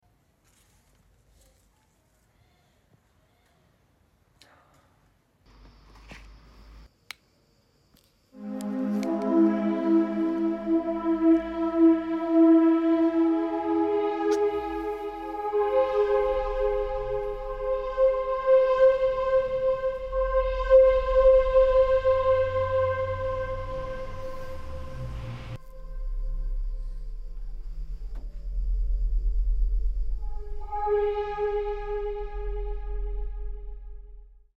Attraverso due elettrodi posizionati sul fiore, il dispositivo PlantsPlay converte il suo bioritmo in musica
in Arcinazzo Romano (RM) 🌹 Through two electrodes placed on the flower, the PlantsPlay device converts its biorhythm into music